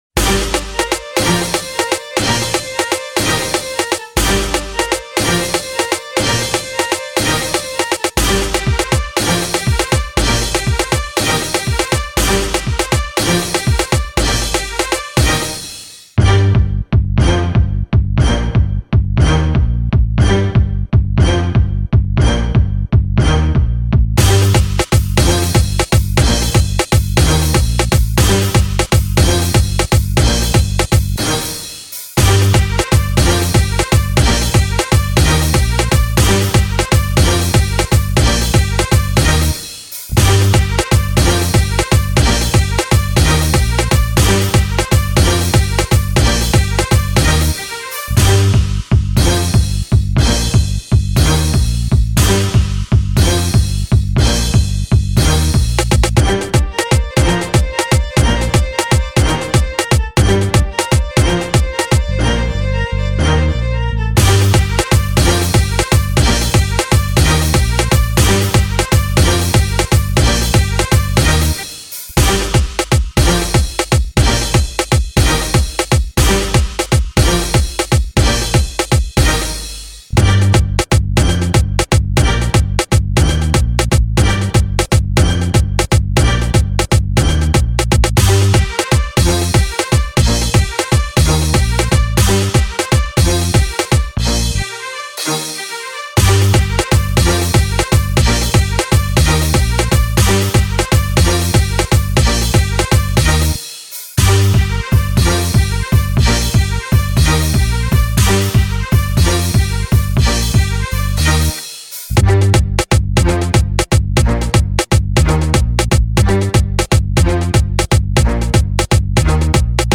Dancehall / Afrobeats Instrumental